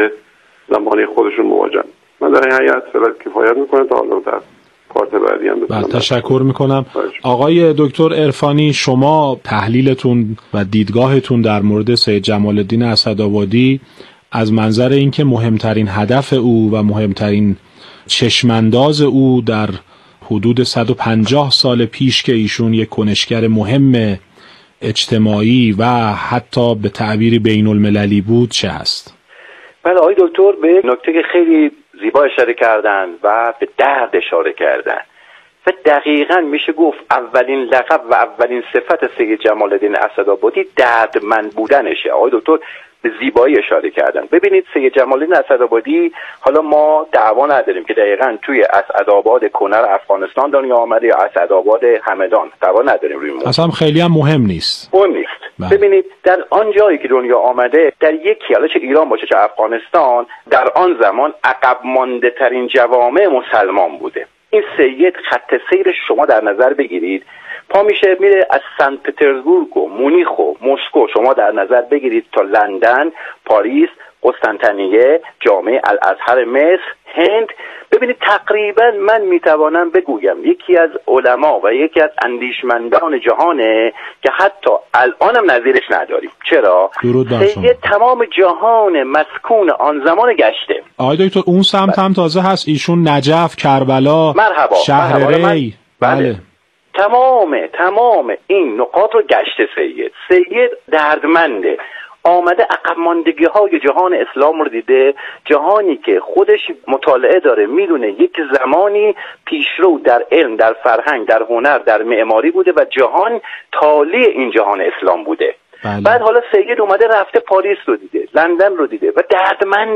به مناسبت سالروز بزرگداشت سید جمال الدین اسد ابادی در برنامه گفتاورد رادیو تهران درباره این مصلح بزرگ جهان اسلام بحث خوبی داشتیم که مشروح ان را درلینکهای زیر قابل شنیدن است .اینکه او روشنفکر بود یا سیاست مدار ؟ به اصلاحات از بالا اعتقاد داشت یا پایین؟